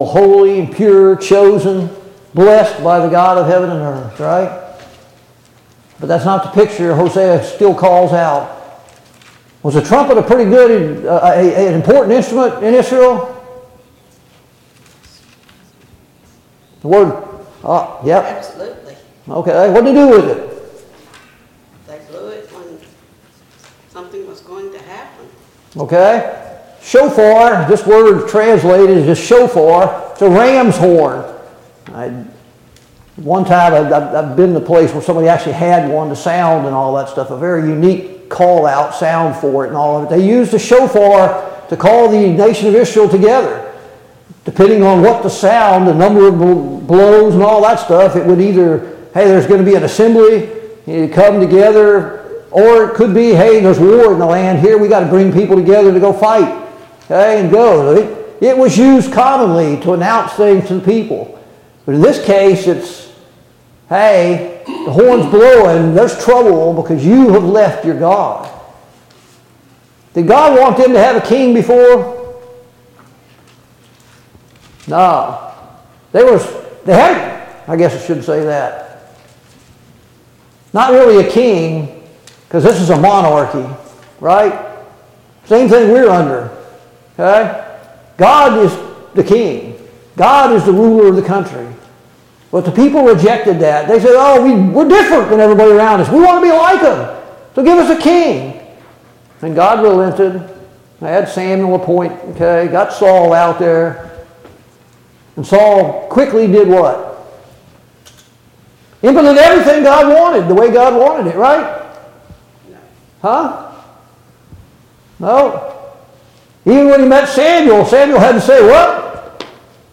Study on the Minor Prophets Passage: Hosea 8-10 Service Type: Sunday Morning Bible Class « 6.